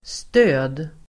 Uttal: [stö:d]